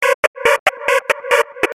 Bass Loop
描述：Simple bass from FL 9 use well :)
标签： 140 bpm Electro Loops Bass Loops 296.25 KB wav Key : Unknown
声道立体声